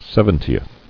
[sev·en·ti·eth]